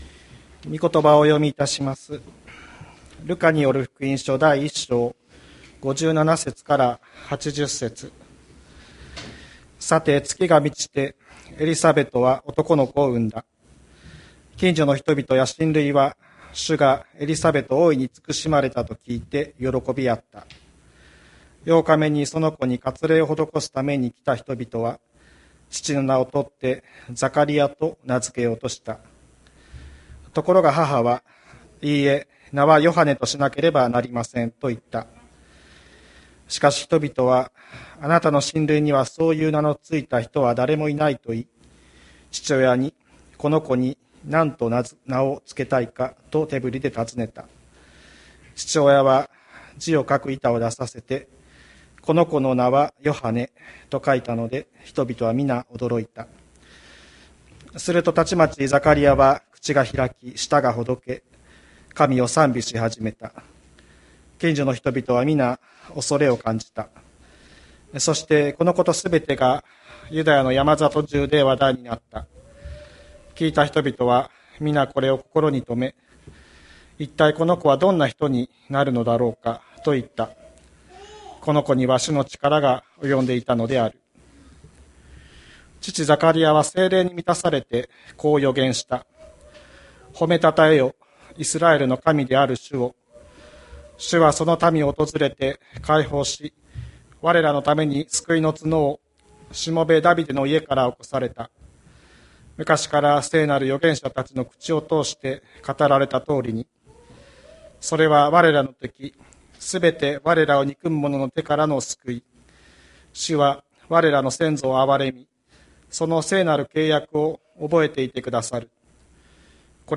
2021年12月12日朝の礼拝「わたしの口に新しい歌を」吹田市千里山のキリスト教会
千里山教会 2021年12月12日の礼拝メッセージ。